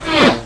flyby_a.wav